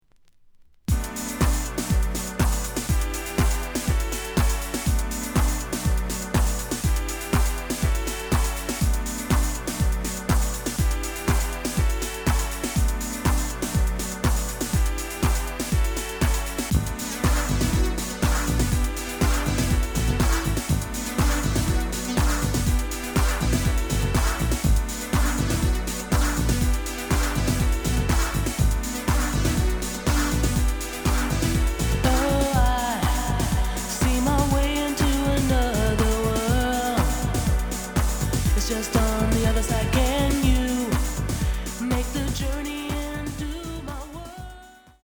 試聴は実際のレコードから録音しています。
The audio sample is recorded from the actual item.
●Genre: House / Techno